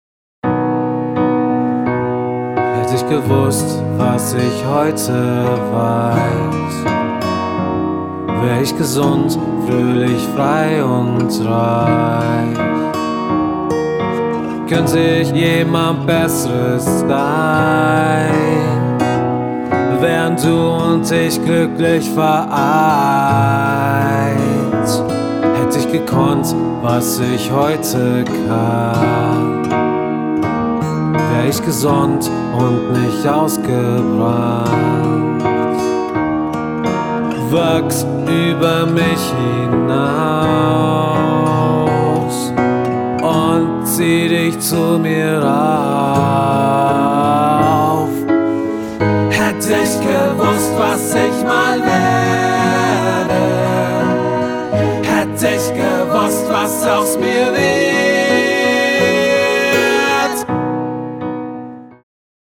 neben den passenden Harmonien auch sehr stimmungsvoll und empathisch das Ganze
Toll, wie es sich aufbaut.